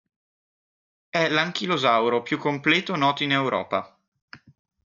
Read more in to into by on marker of way or manner Frequency A1 Hyphenated as ìn Pronounced as (IPA) /ˈin/ Etymology From Latin in.